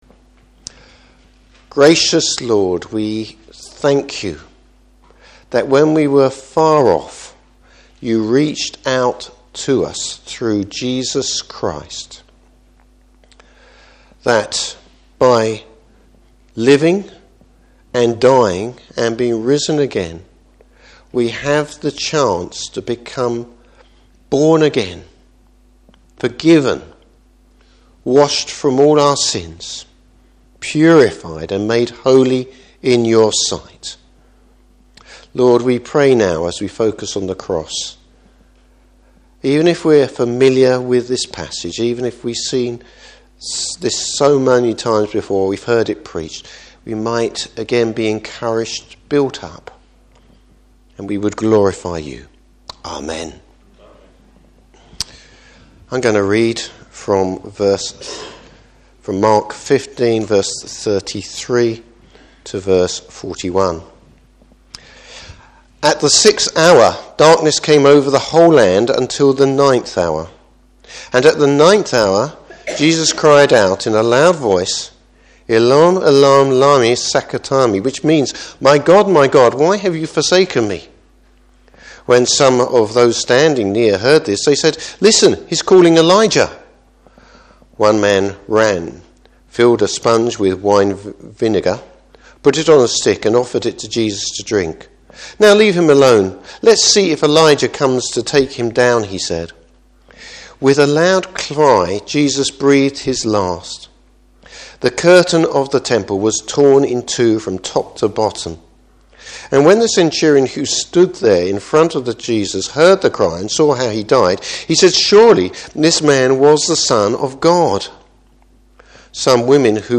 Service Type: Morning Service What does Jesus’ death mean?